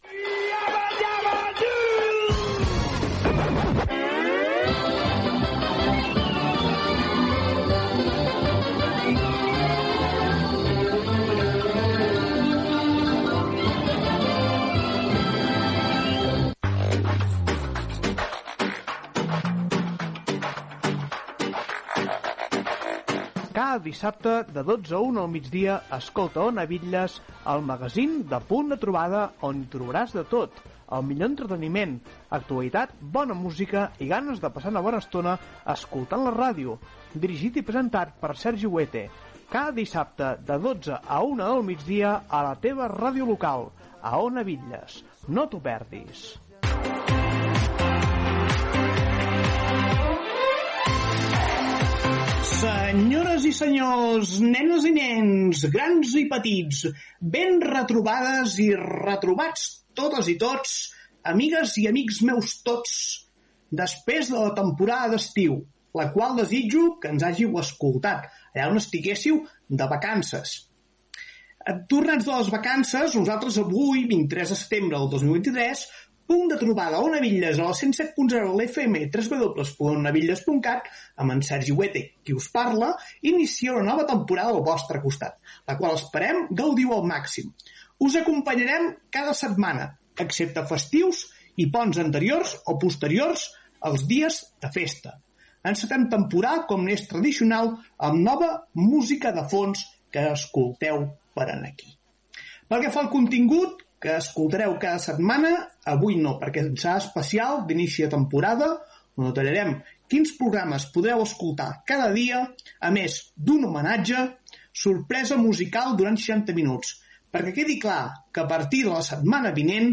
Sintonia del programa, presentació, data, identificació, nova temporada, continguts previstos, tema musical